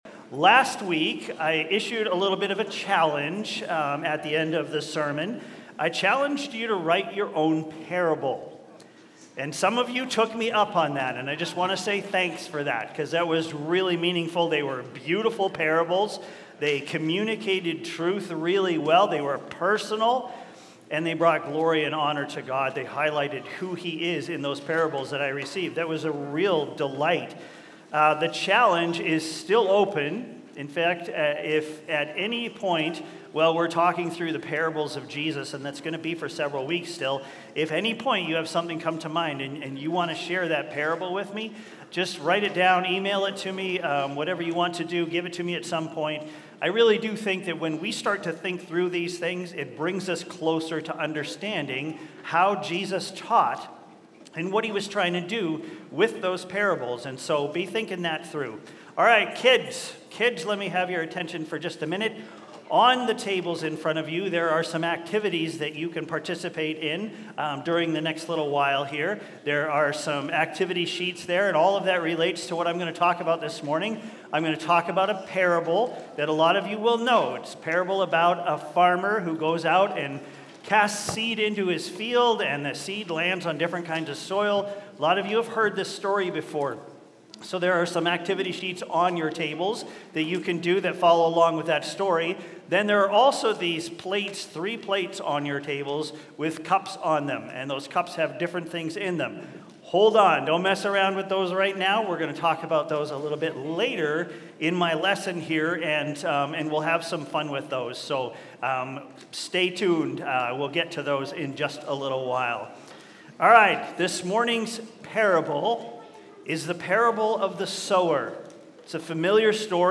Aug 3, 2025 Sermon Audio.mp3